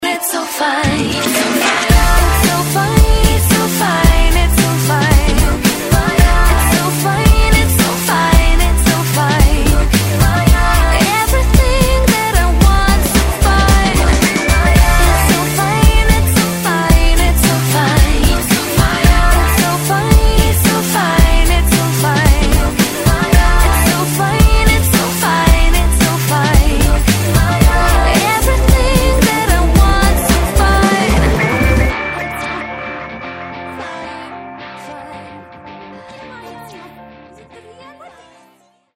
Главная » Файлы » Hip-Hop, RnB, Rap